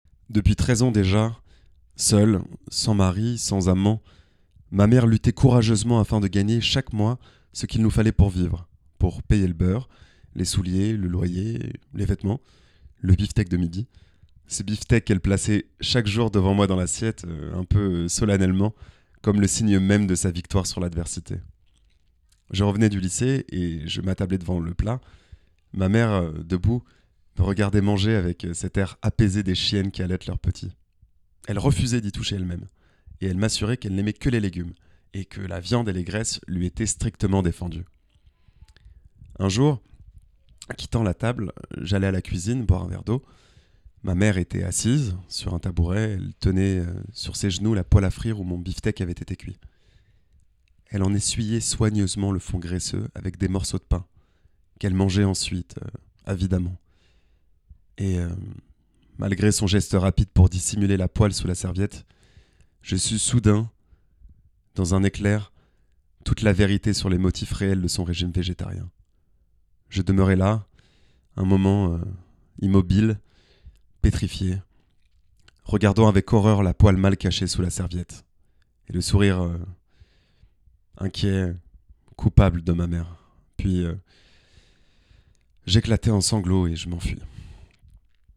Démo audio
Comédien
20 - 40 ans - Baryton